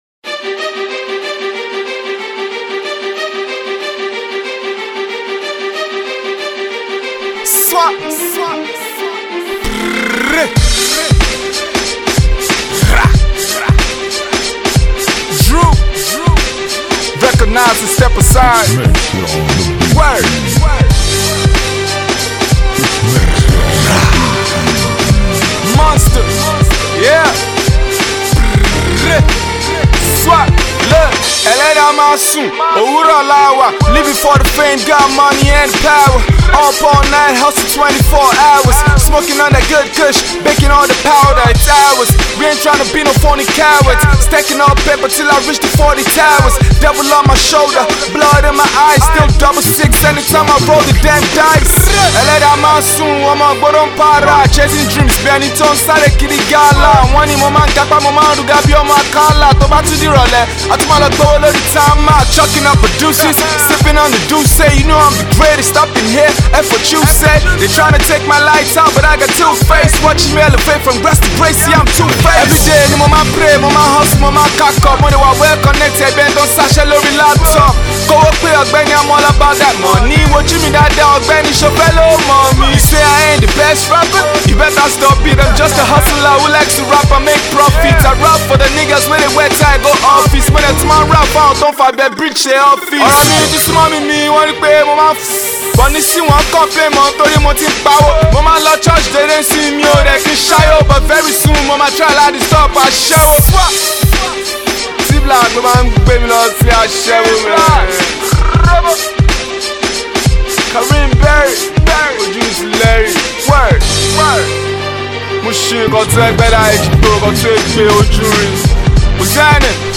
exciting rap tune